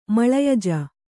♪ maḷayaja